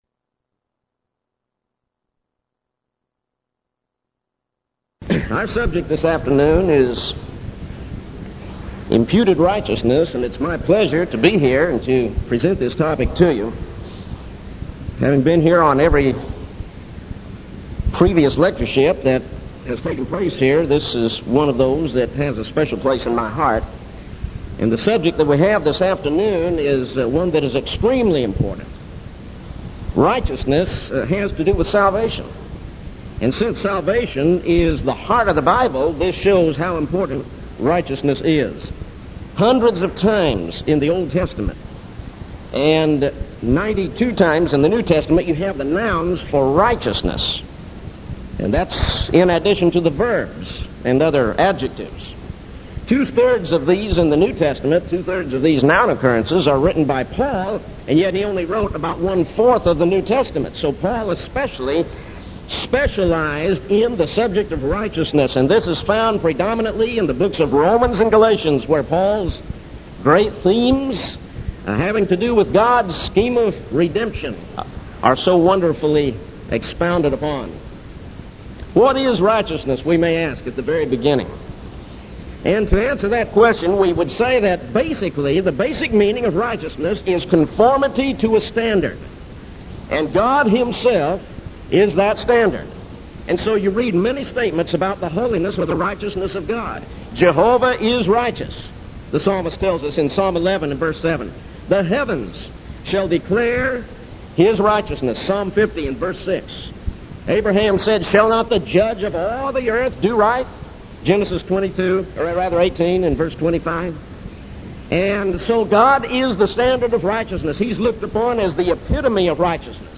Event: 1987 Denton Lectures
lecture